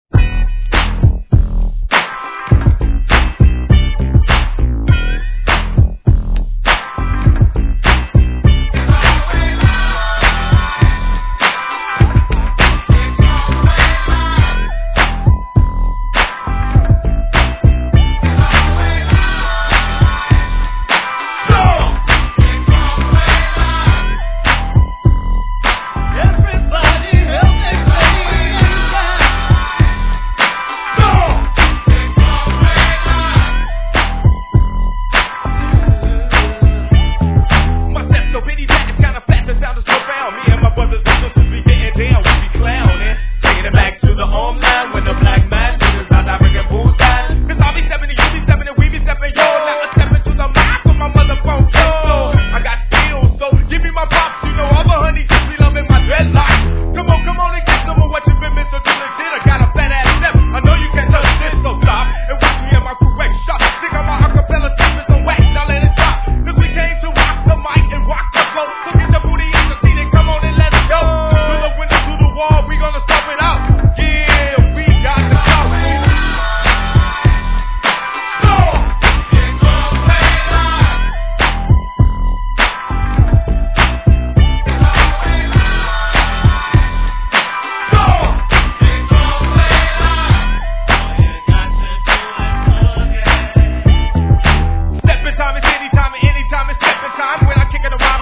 G-RAP/WEST COAST/SOUTH
1995年の哀愁エレピが絡む爽快FUNK!!